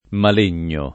[ mal % n’n’o ]